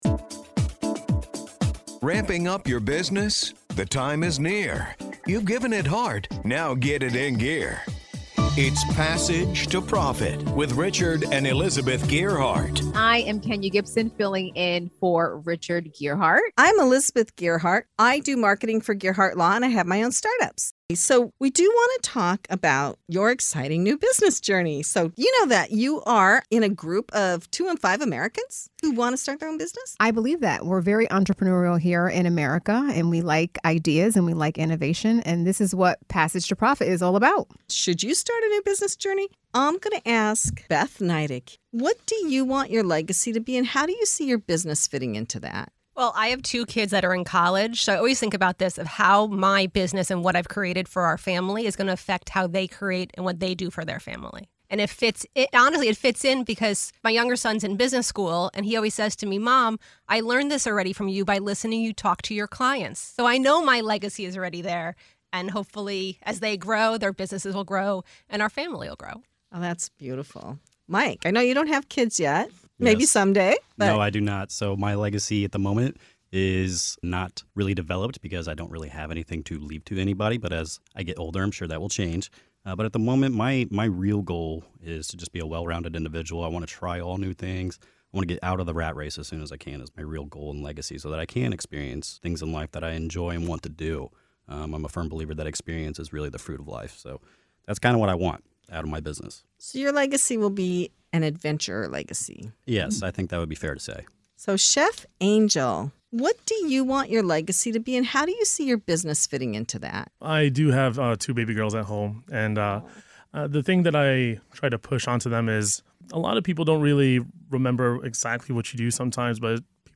As they reflect on their own business journeys, they discuss how their entrepreneurial paths shape not only their careers but also the futures of their families. From teaching integrity and hard work to fostering independence and adventure, each guest shares what legacy means to them and how they aim to leave a lasting impact through their businesses. Tune in for a thoughtful conversation on purpose, passion, and the long-term influence of entrepreneurship.